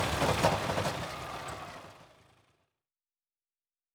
pgs/Assets/Audio/Sci-Fi Sounds/Mechanical/Engine 1 Stop.wav at 7452e70b8c5ad2f7daae623e1a952eb18c9caab4
Engine 1 Stop.wav